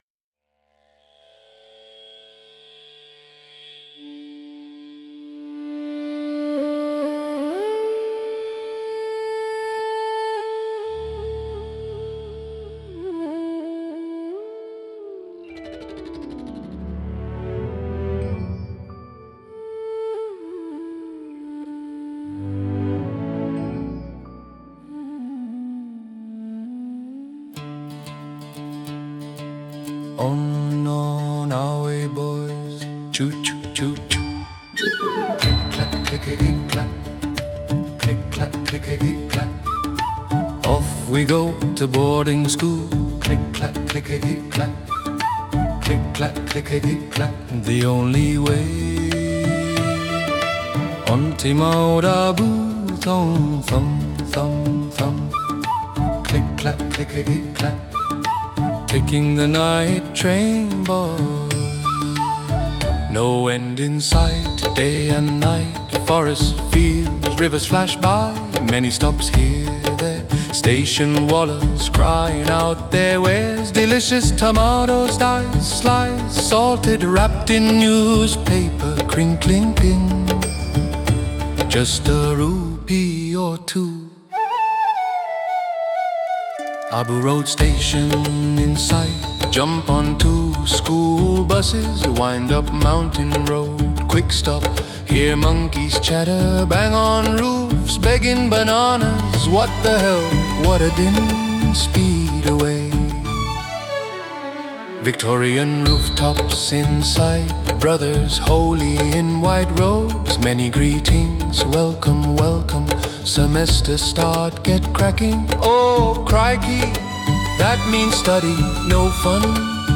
He got some AI song-generation tools going and put together Schoolboy Dream. A curious hybrid of Indian and Australian/Western music, but it's the lyrics that are particularly delightful.